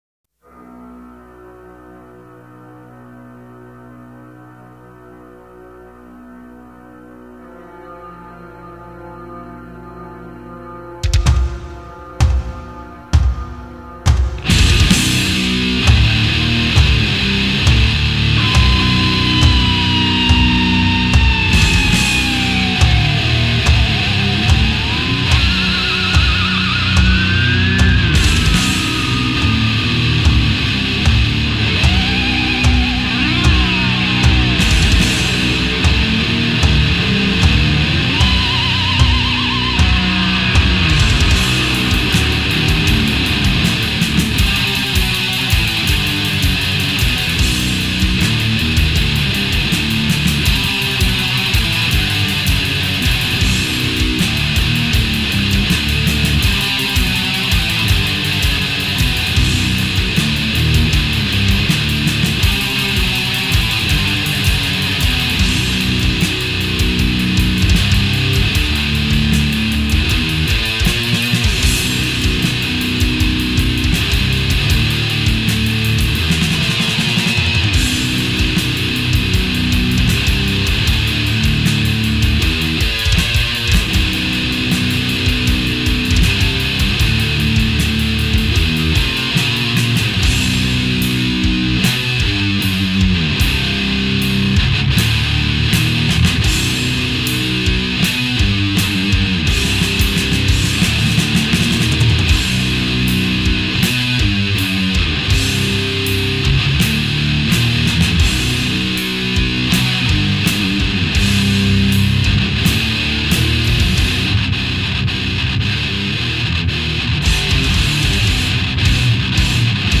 blastin' metal & industrial vibes